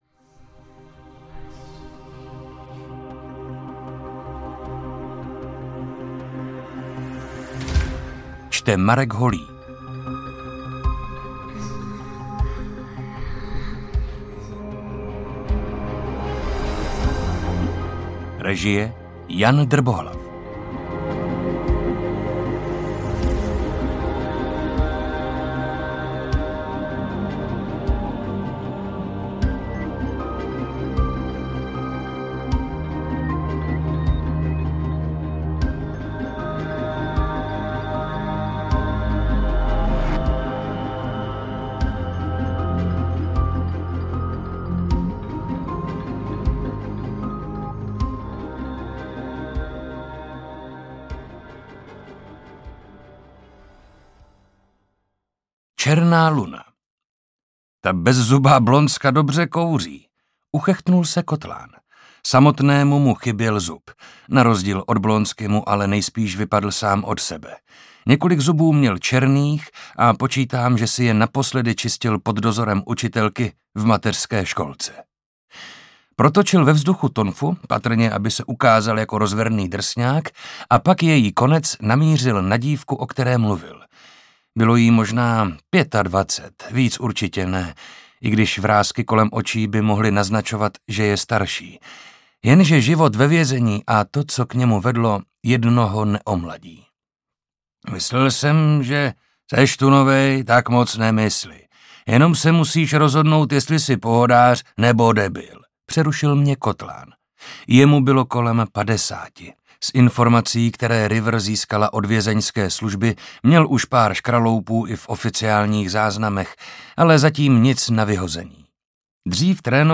Černá luna audiokniha
Ukázka z knihy
• InterpretMarek Holý